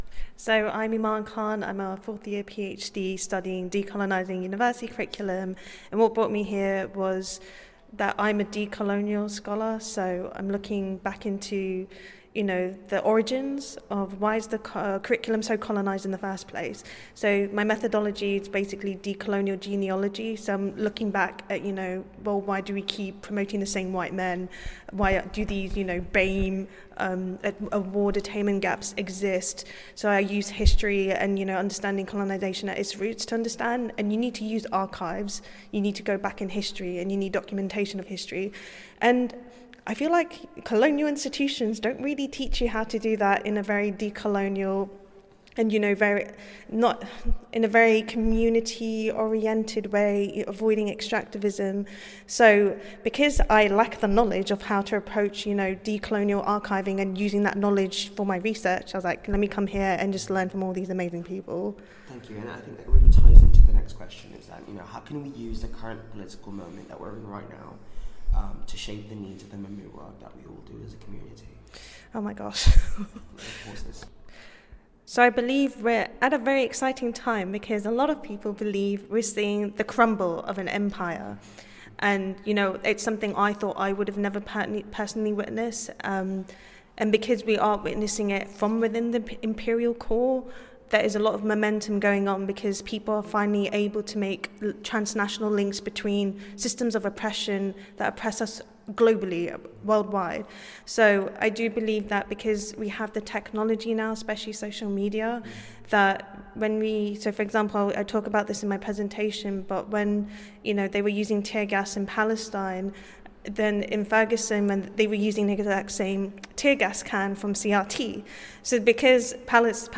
The following audio reflections, recorded during the Brighton convening, feature members of the UK Community of Practice addressing the urgent question: How can we use the current political climate we’re in right now to shape the needs of memory work?